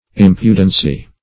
Search Result for " impudency" : The Collaborative International Dictionary of English v.0.48: Impudency \Im"pu*den*cy\, n. Impudence.